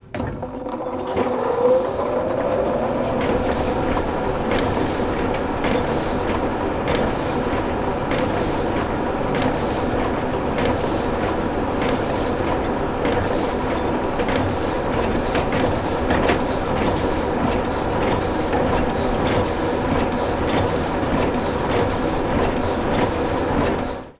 Heidelberg printing press #1
UCS Category: Machines / Industrial (MACHInd)
Type: Alone sound
Channels: Stereo
Conditions: Indoor
Realism: Realistic
Equipment: Zoom H4e